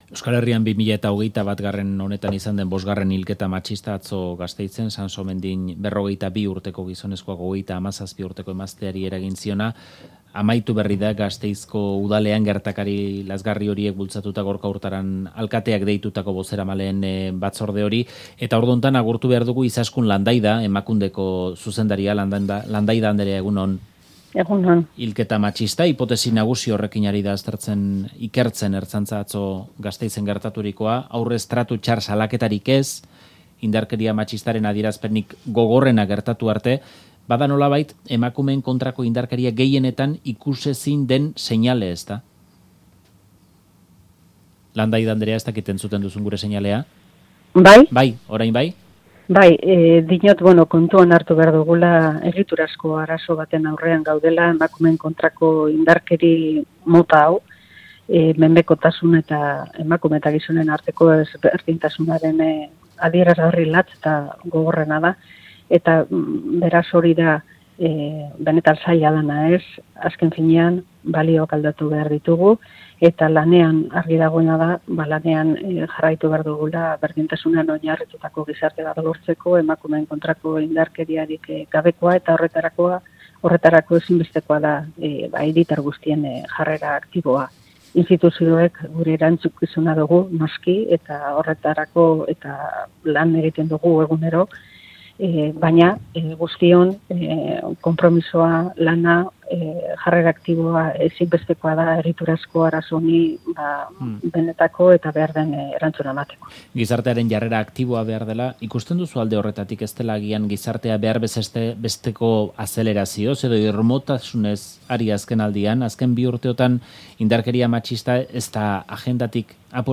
Izaskun Landaida, Emakundeko zuzendaria, Faktorian